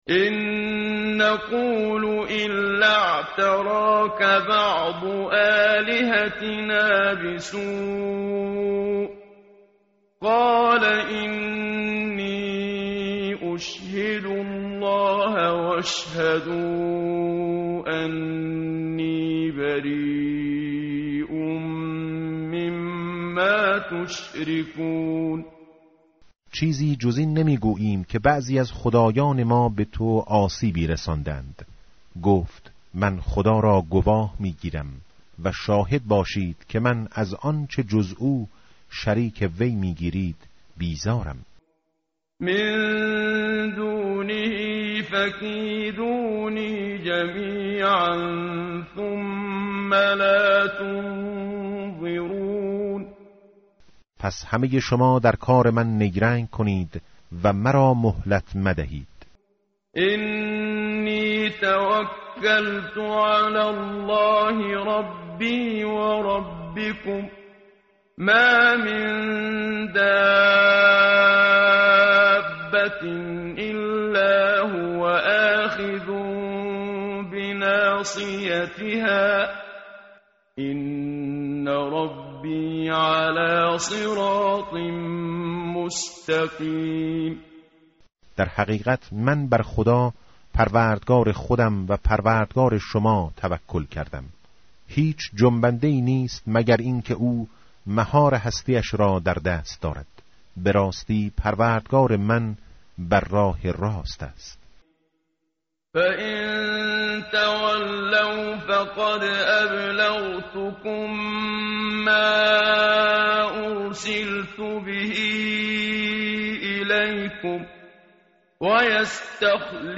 متن قرآن همراه باتلاوت قرآن و ترجمه
tartil_menshavi va tarjome_Page_228.mp3